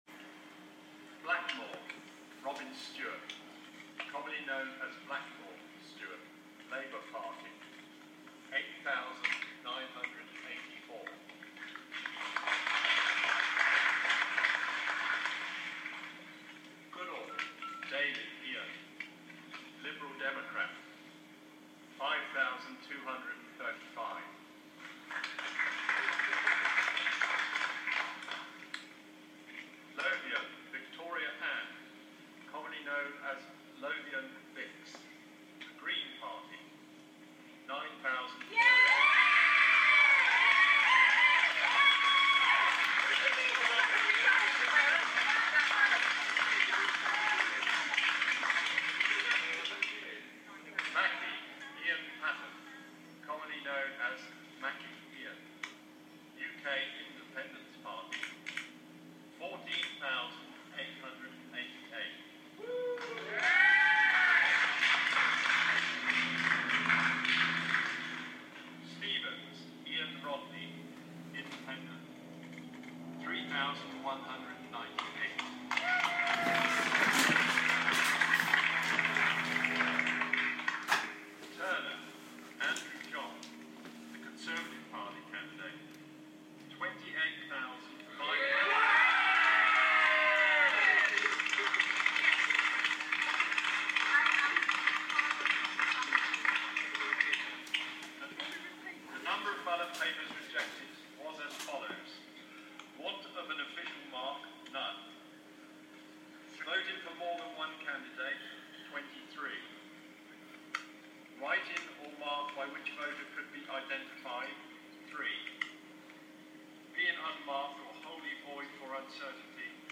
Listen to the official declaration at the General Election Count